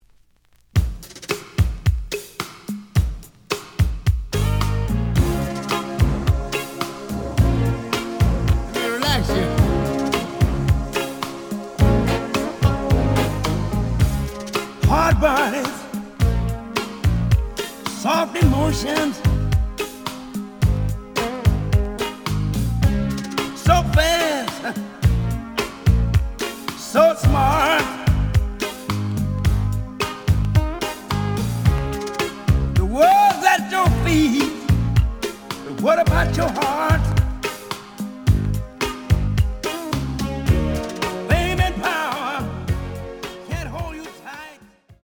試聴は実際のレコードから録音しています。
●Format: 7 inch
●Genre: Funk, 80's / 90's Funk